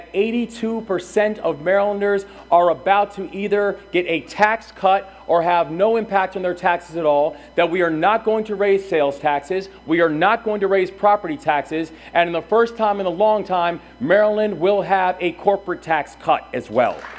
At a presentation in College Park, Moore said his proposed budget actions would lighten the load on the majority of citizens in the state…